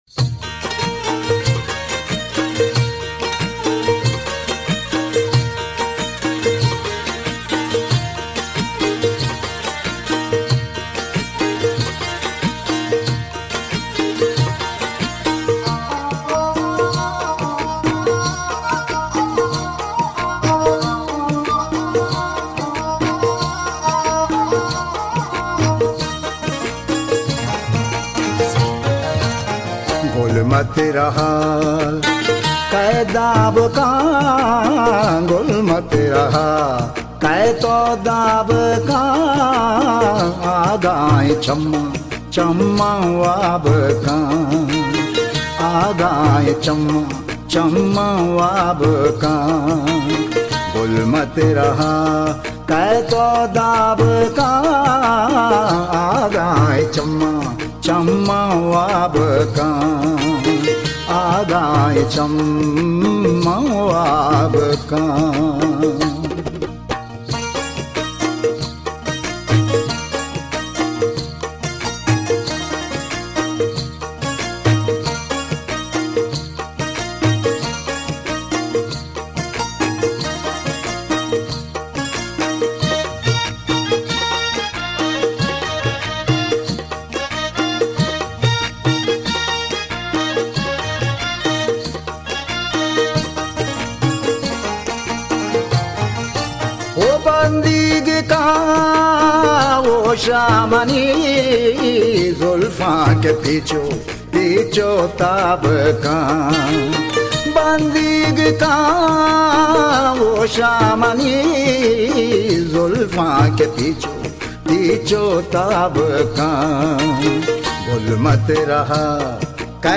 موسیقی بلوچستان